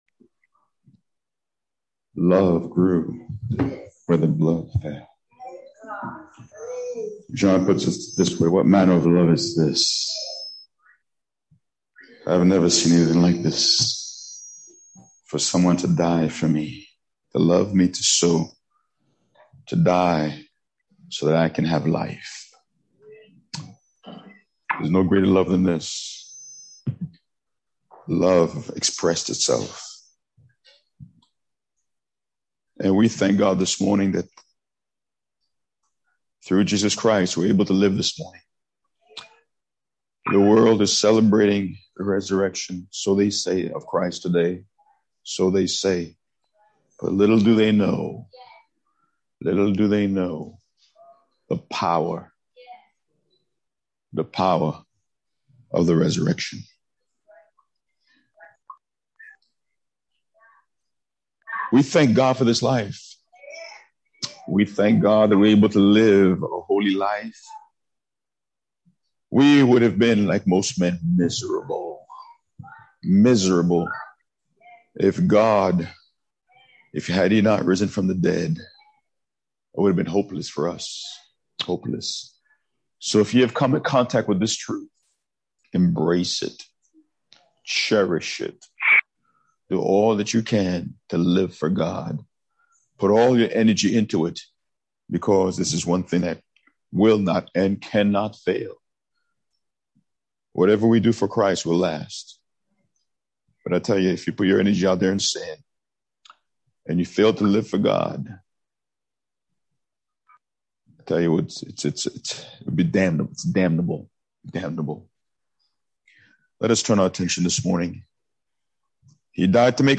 Sermons - Arverne Church of God